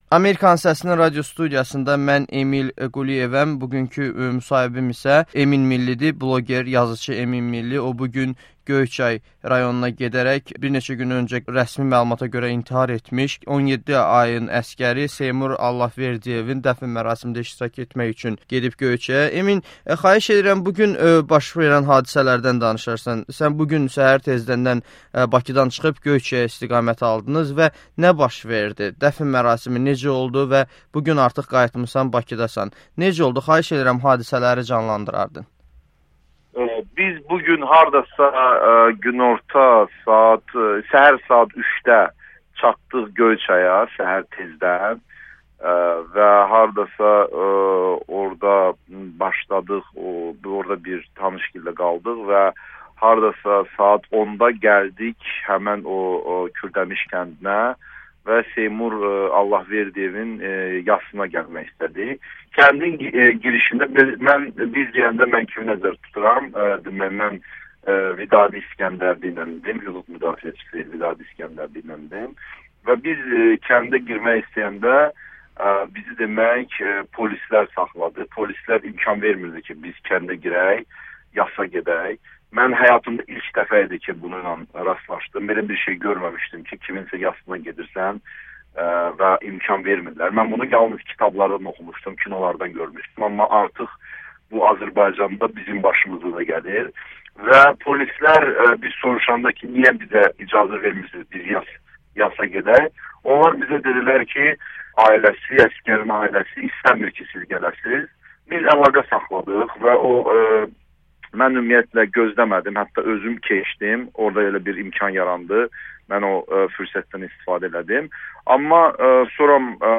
[Müsahibə]